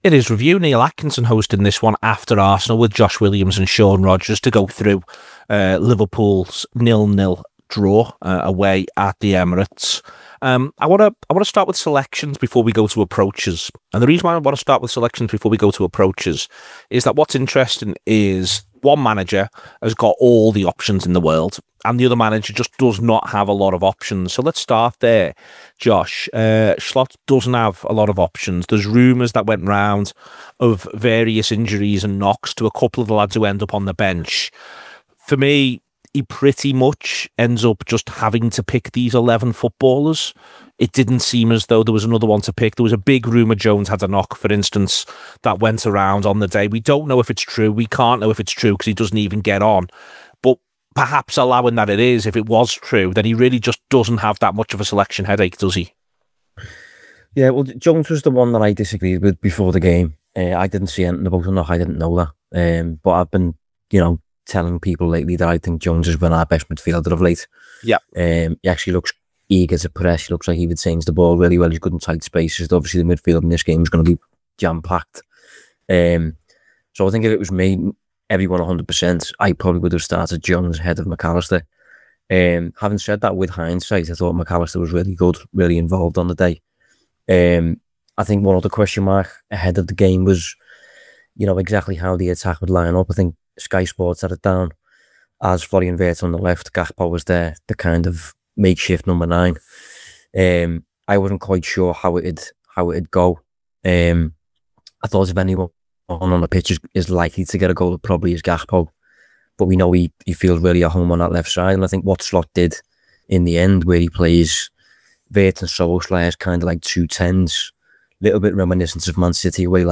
Below is a clip from the show – subscribe to The Anfield Wrap for more review chat…